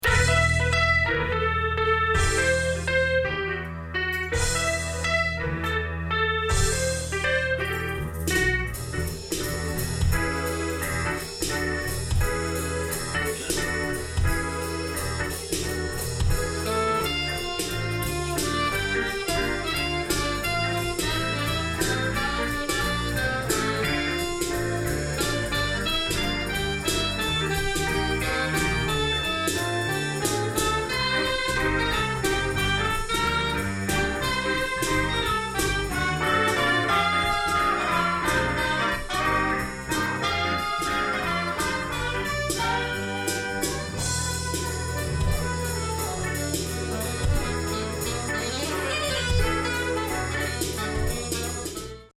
arrangement and play saxphone
(LIVE)